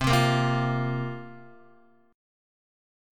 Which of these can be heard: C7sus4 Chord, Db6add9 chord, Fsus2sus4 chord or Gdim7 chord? C7sus4 Chord